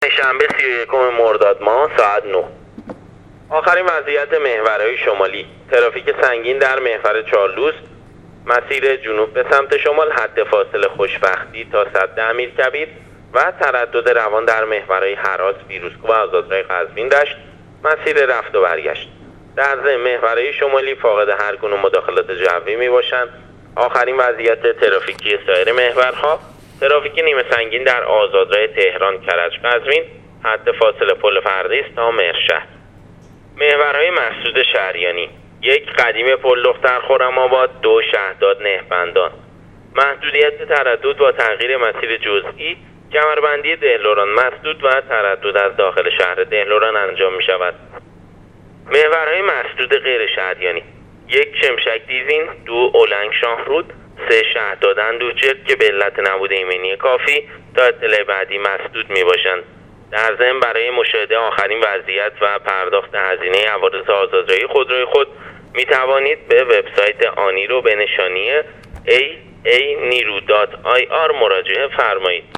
گزارش رادیو اینترنتی پایگاه خبری وزارت راه و شهرسازی را از آخرین وضعیت جوی و ترافیکی جاده‌های کشور بشنوید.